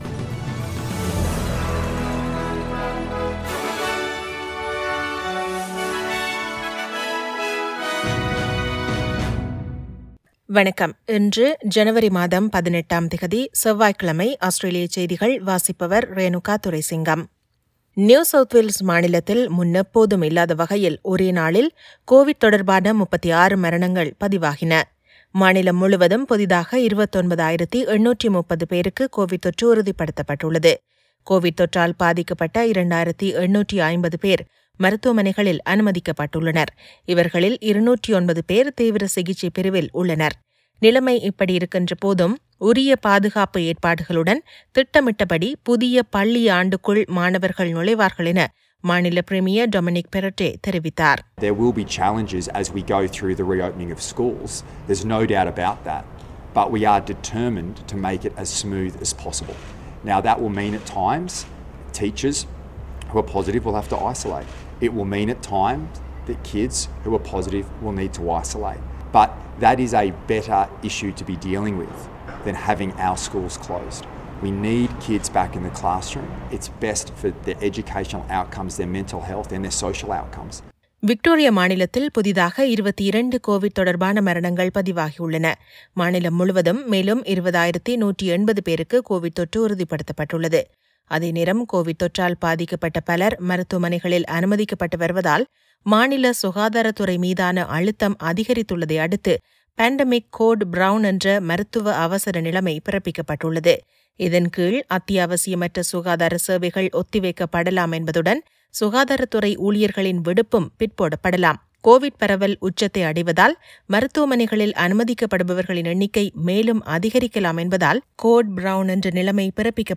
Australian news bulletin for Tuesday 18 Jan 2022.